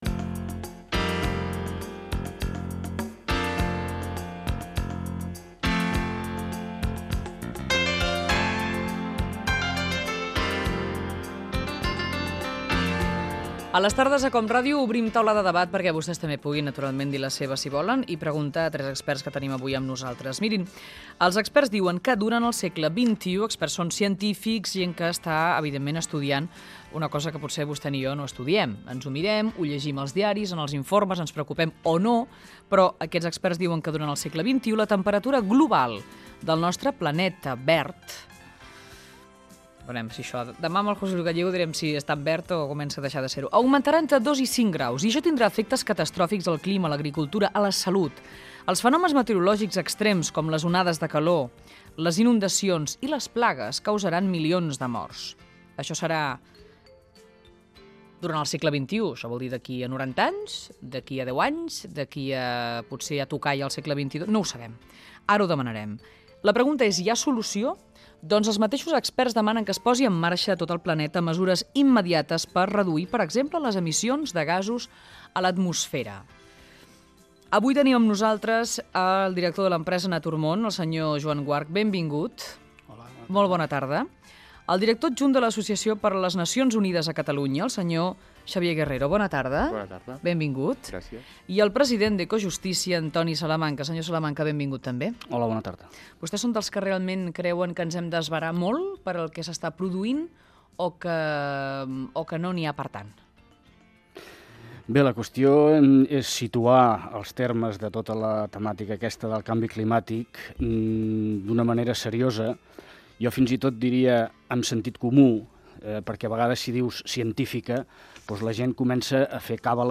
Taula de debat sobre el canvi climàtic al segle XXI
Gènere radiofònic Entreteniment Presentador/a Roca, Elisenda Data emissió 2000-05-03 Banda FM Localitat Barcelona Comarca Barcelonès Durada enregistrament 10:02 Idioma Català Notes Fragment extret de l'arxiu sonor de COM Ràdio.